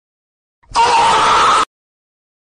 Sound effects - Emoji disappear